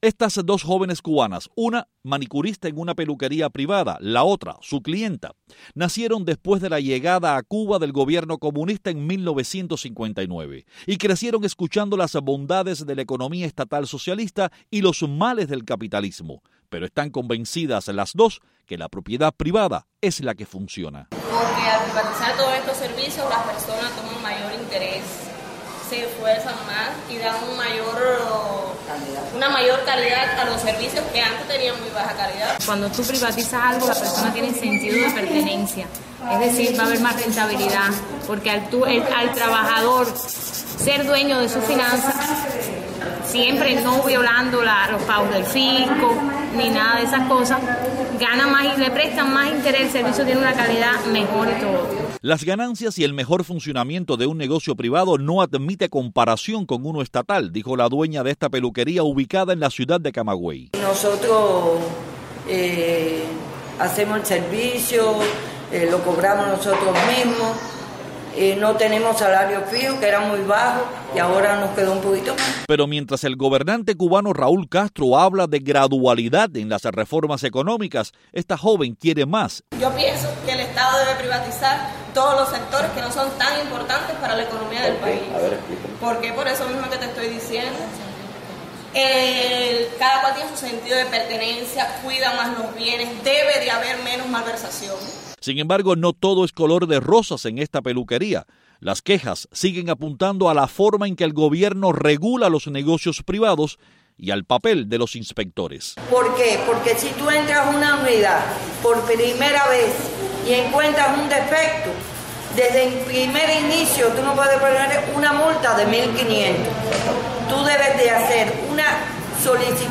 Trabajadoras y clientes de una peluquería en Cuba defienden la privatización de los negocios y creen que más servicios deben estar en manos de particulares.